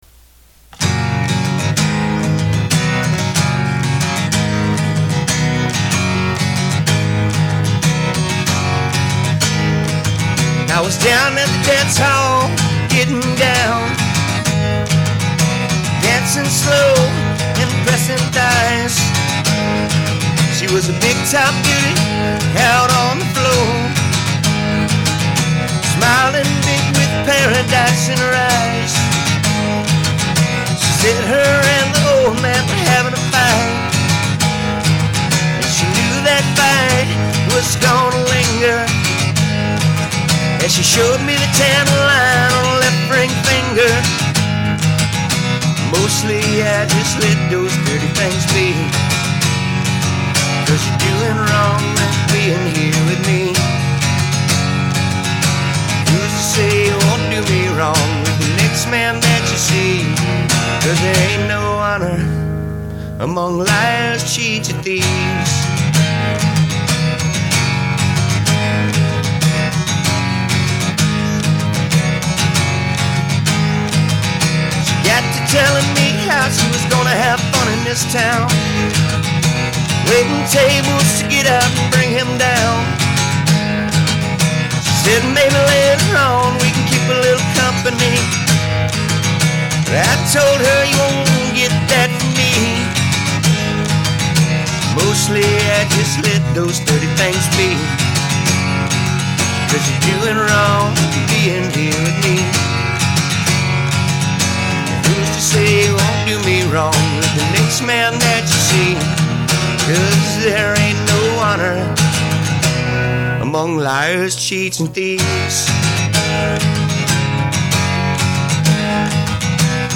Country
Folk
Country-rock